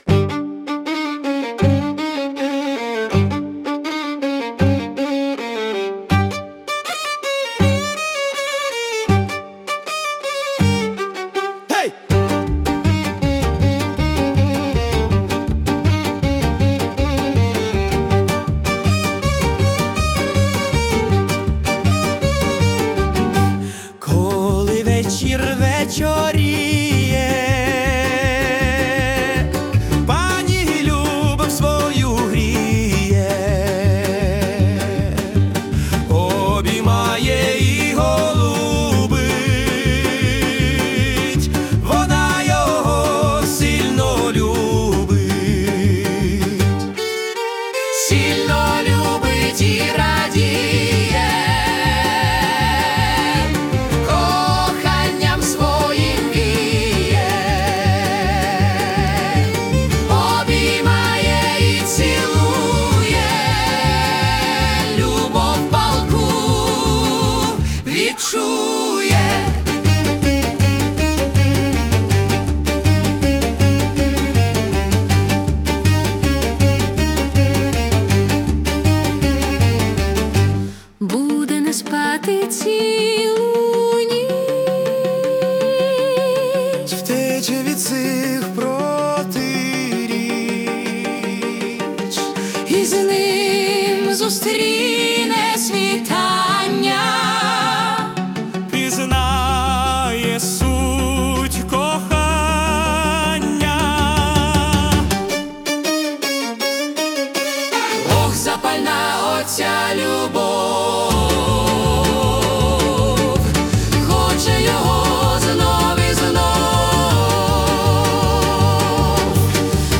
́Коломийка (Пісня)
СТИЛЬОВІ ЖАНРИ: Ліричний